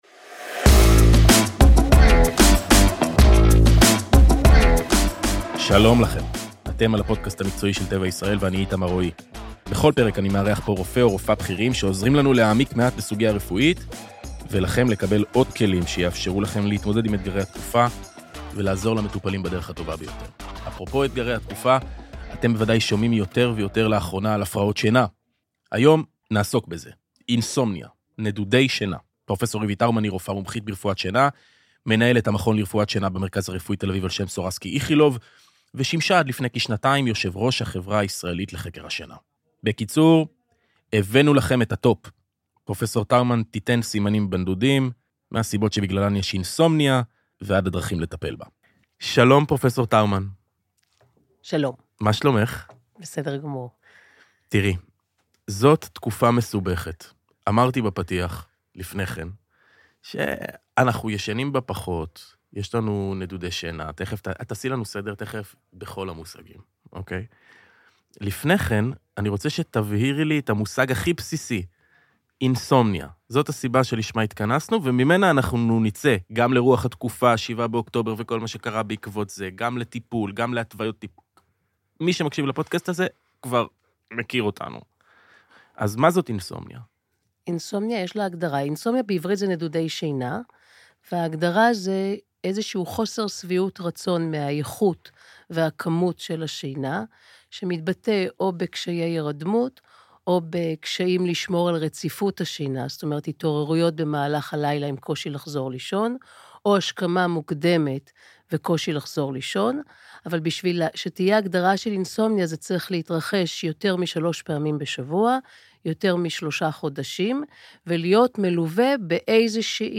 בכל פרק בפודקאסט נארח רופאים מומחים מדיסיפלינות טיפוליות שונות ונדון בסוגיות רפואיות שמעסיקות את כולנו. הפרקים יעסקו בנושאים שונים החל מטיפול ואבחון מיגרנה, הפרעת קשב במבוגרים, תזונת תינוקות, הפרעות שינה ועוד.